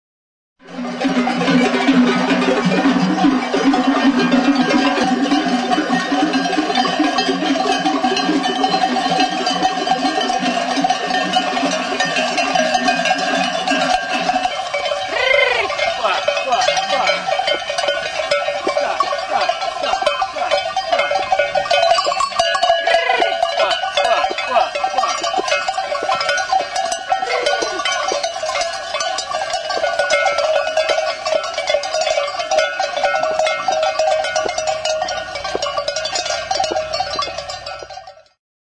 Idiophones -> Struck -> Indirectly
Faltzesko artaldearekin transumantzia.
Sekzio sasi errektangularra duen ezkila handia da.
Larruzko zintarekin lotutako ezpelezko mihia du.